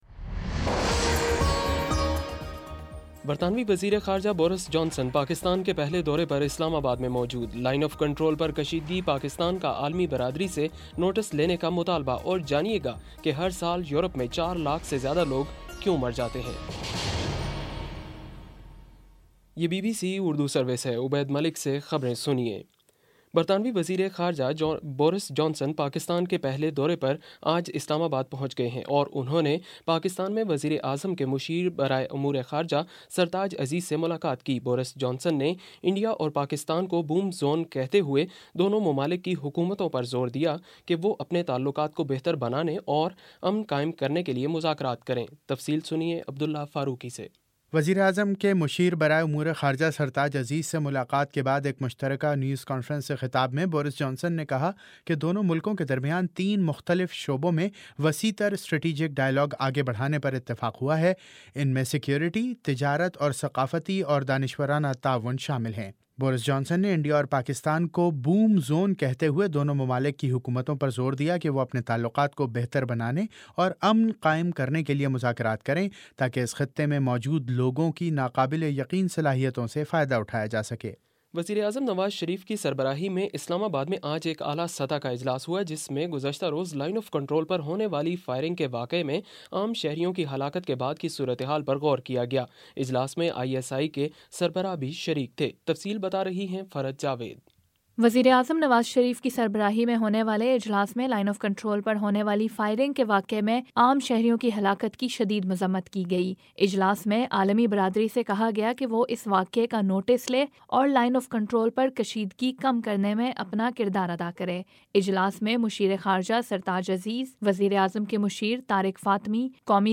نومبر 24 : شام چھ بجے کا نیوز بُلیٹن